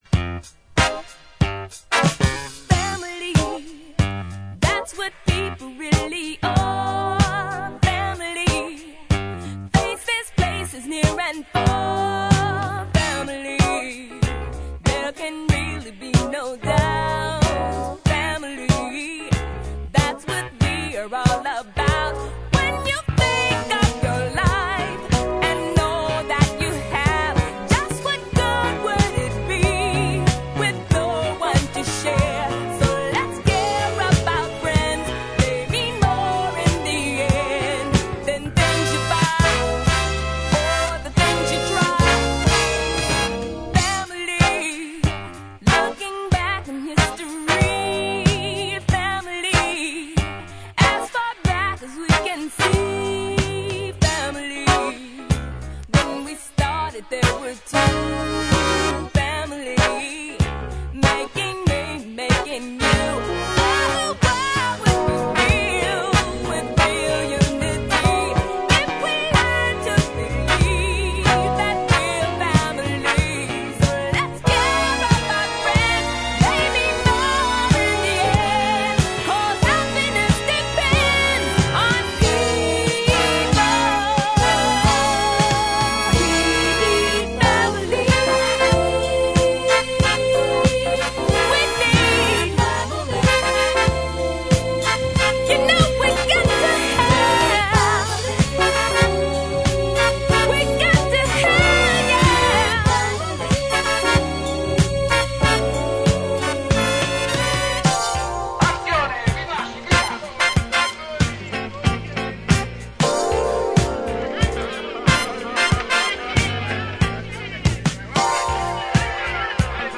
ジャンル(スタイル) SOUL / JAZZ